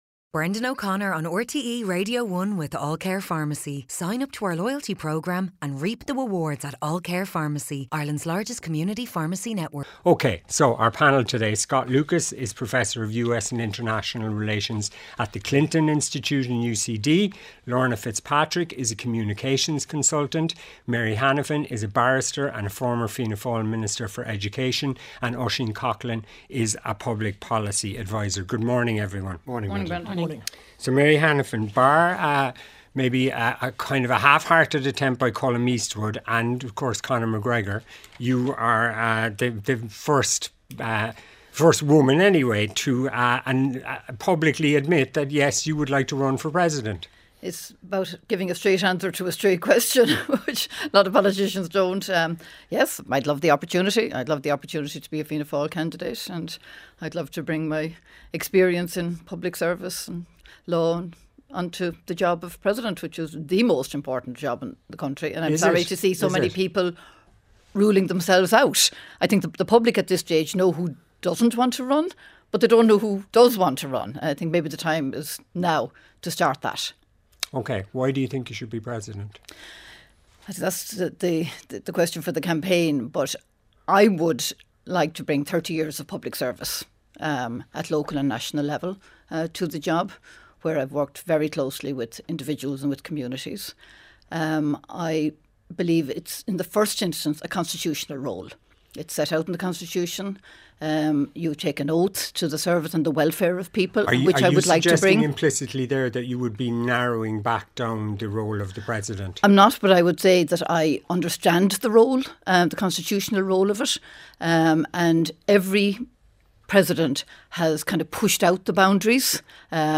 Newspaper Panel